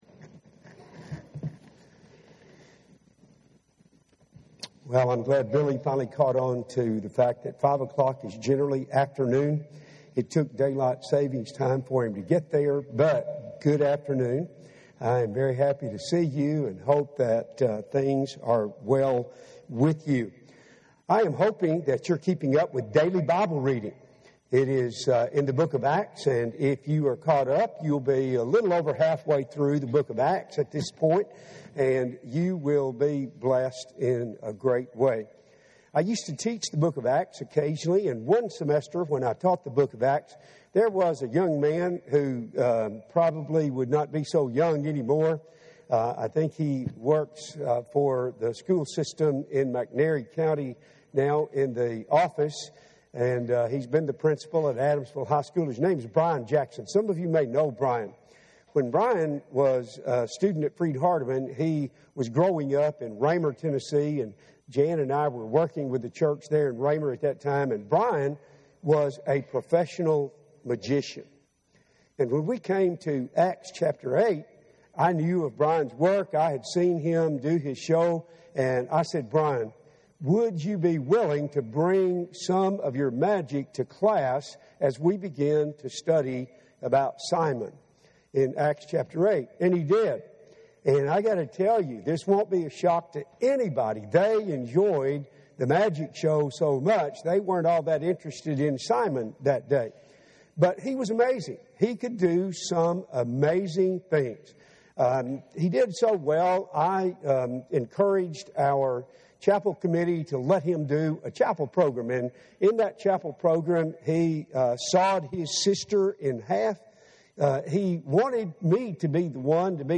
The Signs Point to Jesus – Henderson, TN Church of Christ